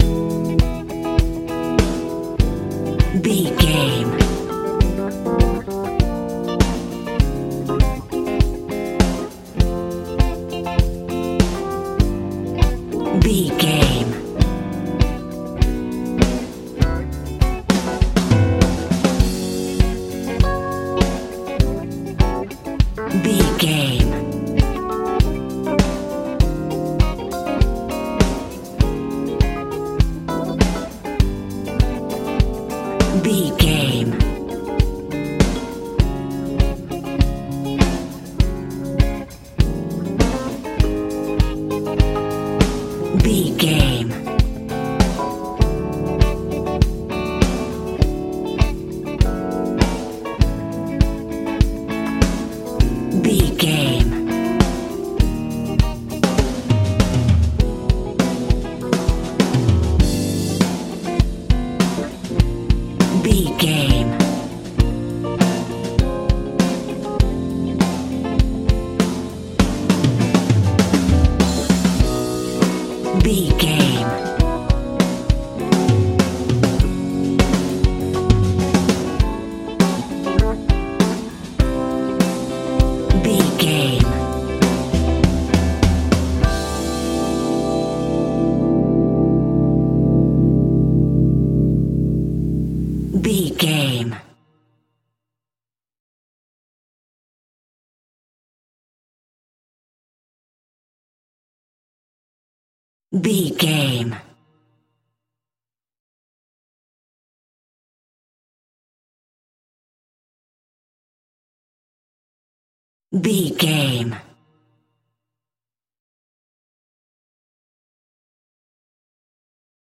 rnb funk music
Ionian/Major
groovy
funky
organ
electric guitar
bass guitar
drums
70s
80s
driving
energetic